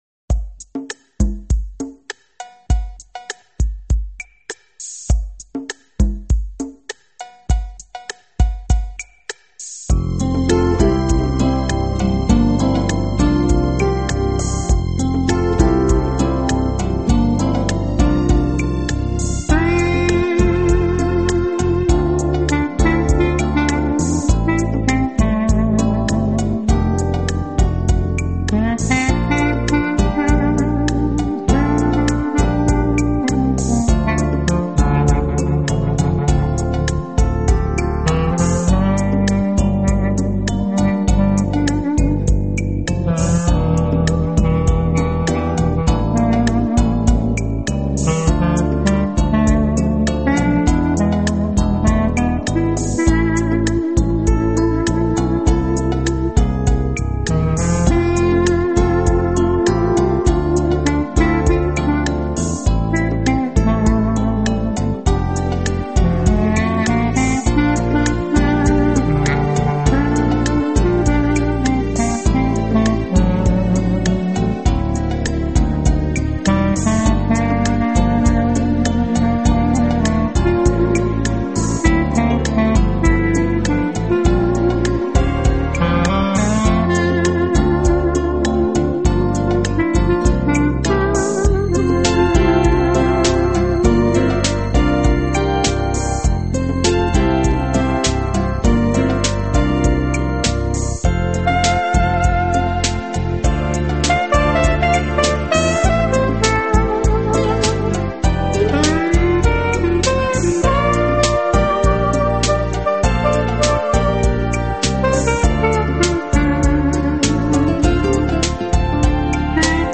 【黑管】
【黑管专辑】